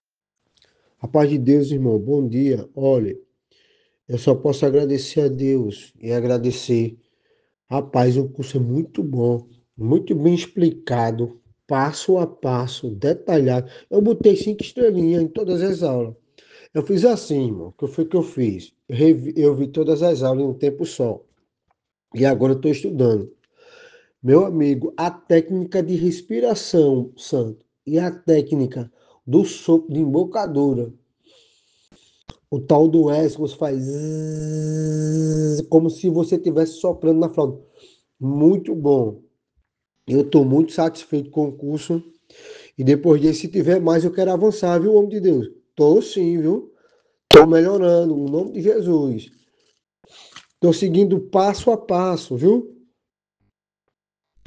Ouça o que diz um de nossos alunos